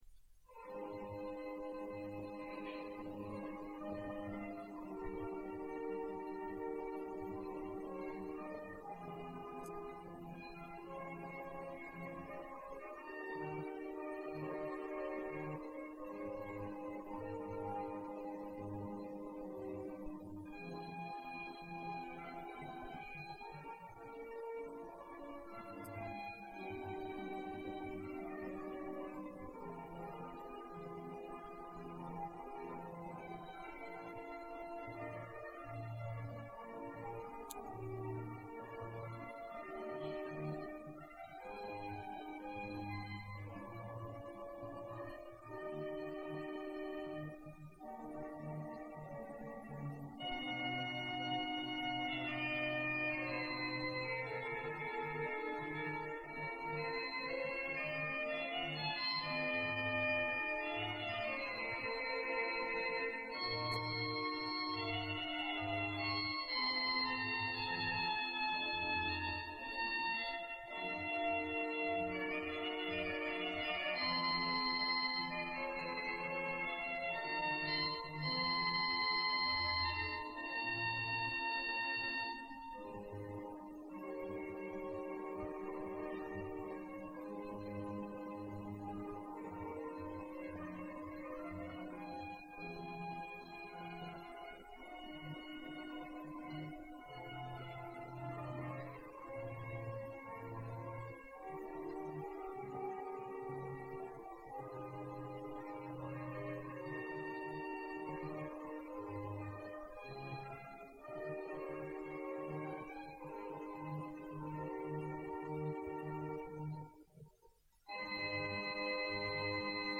Los primeros conciertos de órgano
Algunos fragmentos audio del concierto de ICADE, pero de una calidad regular, según los medios técnicos de aquella época.